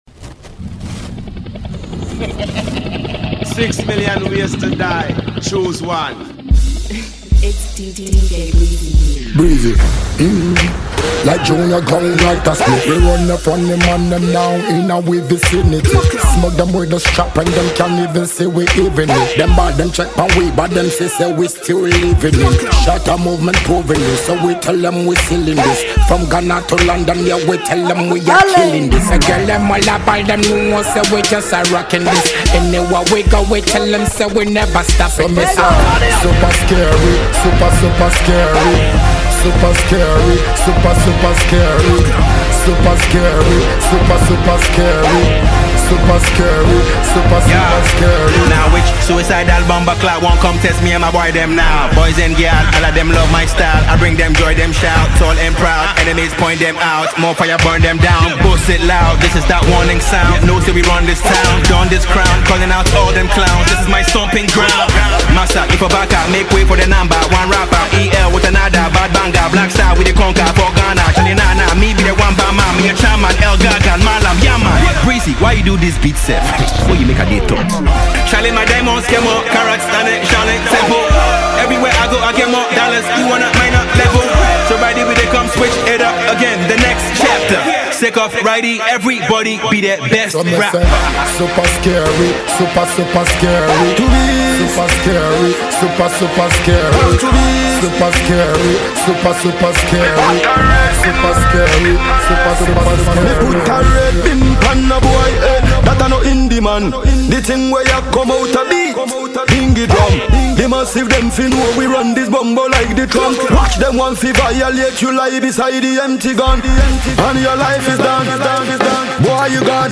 Multi-talented Ghanaian and International disc jockey